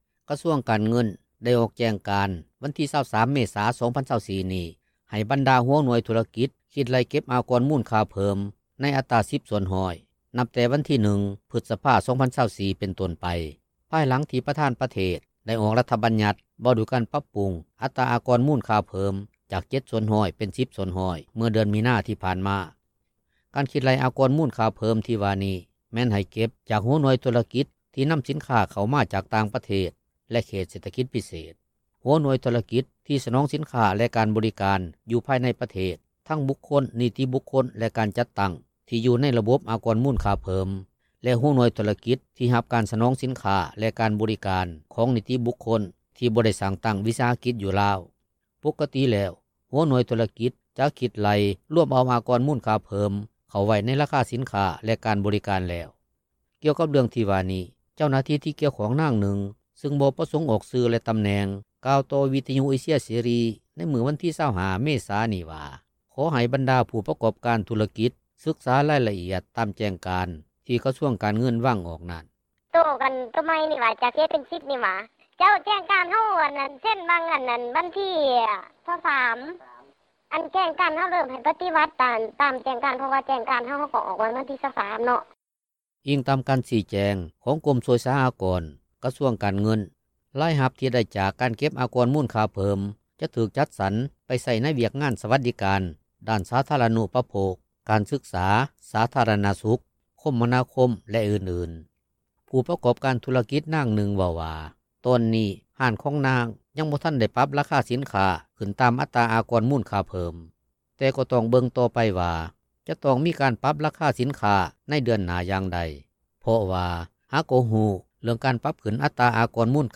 ຜູ້ປະກອບການທຸລະກິດ ນາງນຶ່ງ ເວົ້າວ່າ ຕອນນີ້ ຮ້ານຄ້າຂອງນາງ ຍັງບໍ່ທັນໄດ້ປັບລາຄາສິນຄ້າ ຂື້ນຕາມອັດຕາອາກອນມູນຄ່າເພີ່ມ. ແຕ່ກໍຕ້ອງເບິ່ງຕໍ່ໄປວ່າ ຈະຕ້ອງມີການປັບລາຄາສິນຄ້າ ໃນເດືອນໜ້າຢ່າງໃດ ເພາະວ່າ ຫາກໍຮູ້ ເລື່ອງການປັບຂື້ນອັດຕາອາກອນມູນຄ່າເພີ່ມ ເມື່ອບໍ່ດົນມານີ້d
ຂະນະທີ່ ຊາວບ້ານ ຜູ້ຮູ້ເລື່ອງນີ້ ນາງນຶ່ງ ເວົ້າວ່າ ການທີ່ລັດຖະບານ ຕ້ອງໄດ້ຂື້ນອັດຕາອາກອນມູນຄ່າເພີ່ມນັ້ນ ກໍອາດຈະເປັນຍ້ອນລັດຖະບານ ບໍ່ມີເງິນໃນຄັງແຮສຳຮອງ ອັນຈະເຮັດເກີດຄວາມຫຍຸ້ງຍາກ ເປັນຕົ້ນປະຊາຊົນ ຜູ້ທີ່ຫາເຊົ້າກິນຄ່ຳ.